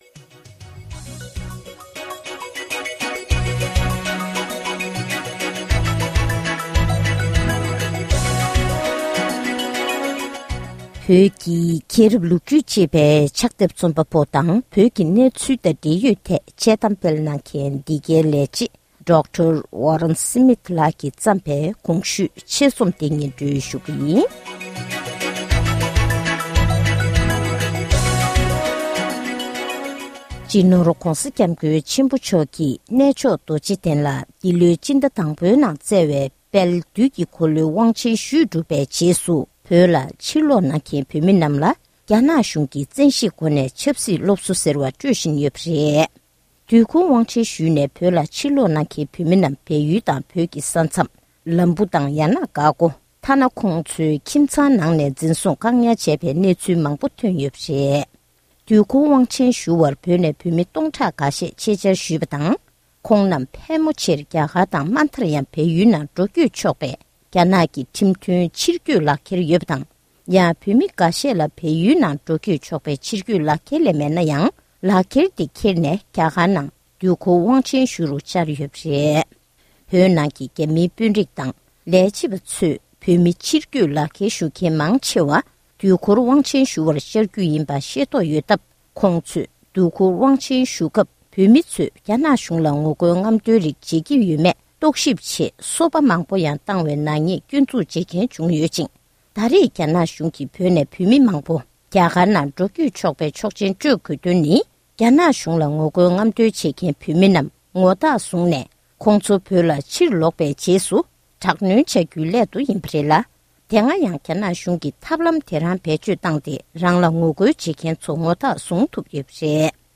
ཕབ་བསྒྱུར་གྱིས་སྙན་སྒྲོན་ཞུས་པར་གསན་རོགས༎